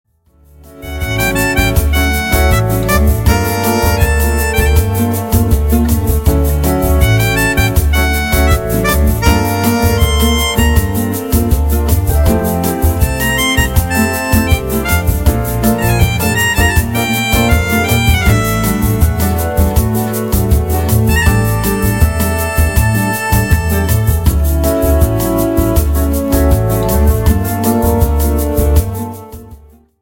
JAZZ  (02.51)